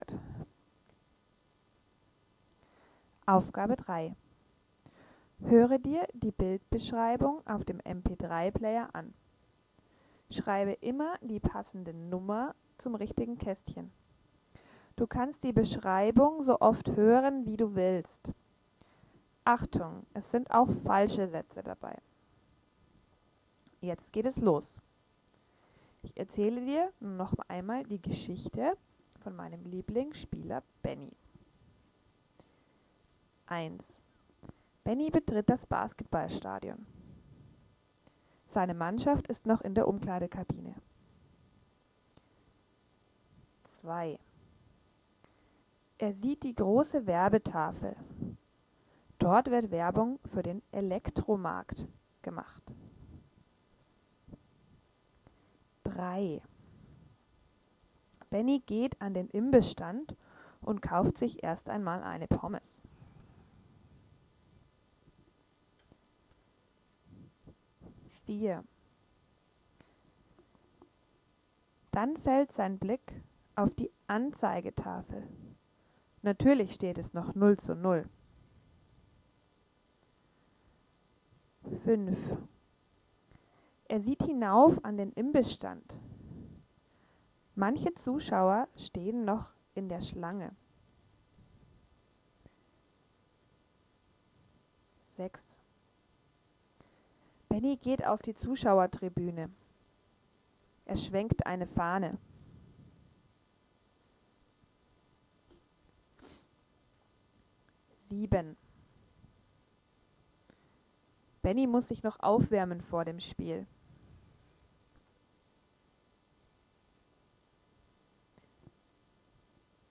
Art des Materials: Arbeitsblätter, Stationenarbeit, Spielideen, Unterrichtsmaterialien, Tafelbild, Hörspiel